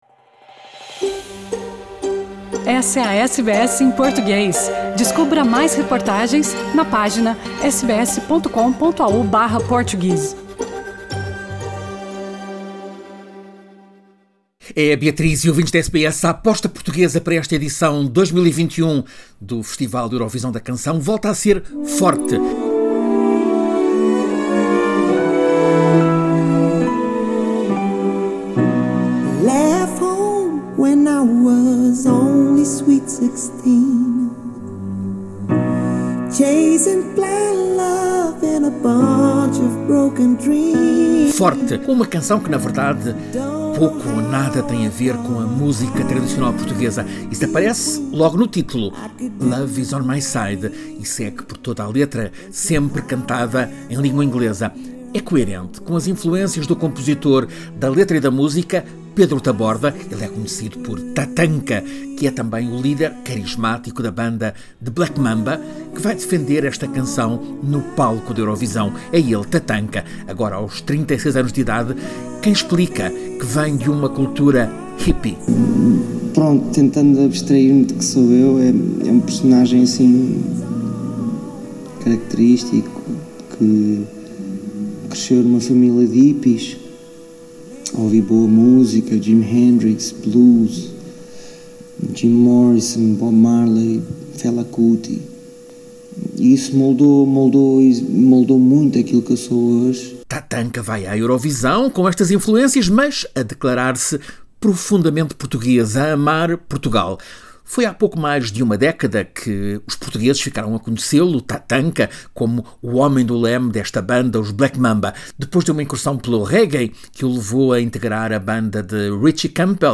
Confira a crônica desta semana